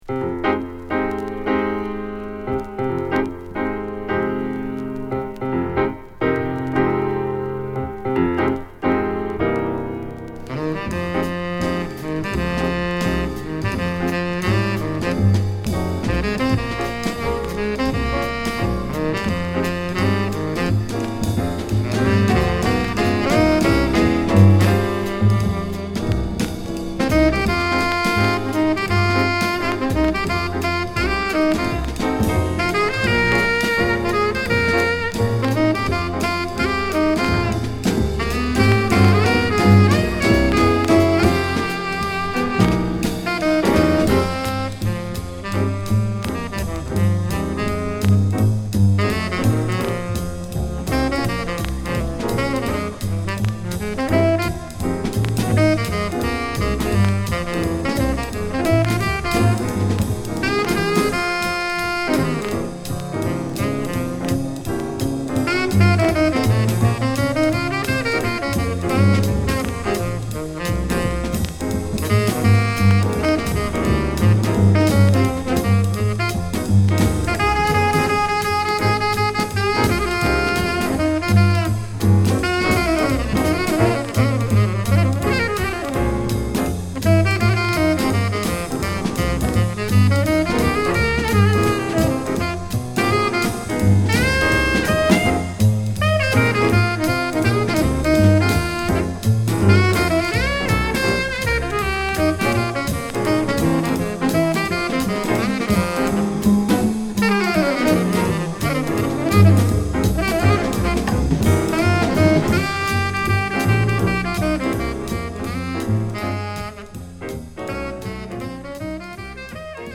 メロウ〜弾んだモーダルジャズを披露！
＊盤面擦れ多いです。